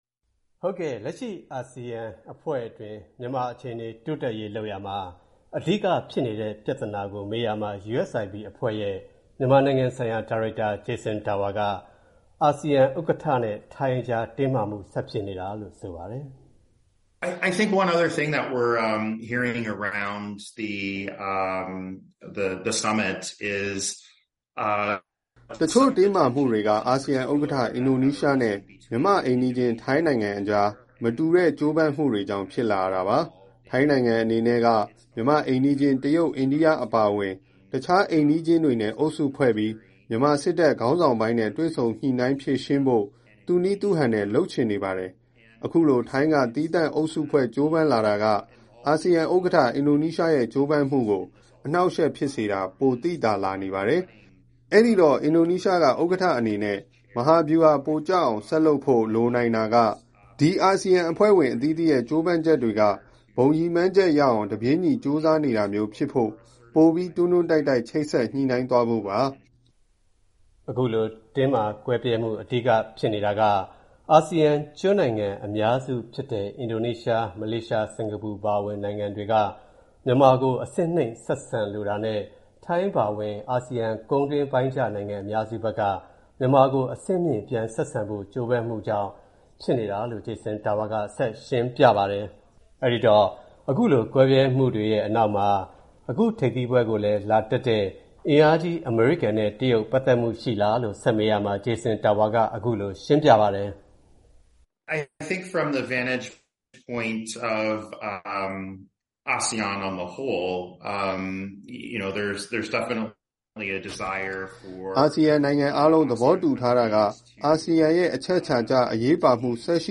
သီးသန့်အင်တာဗျုးမှာ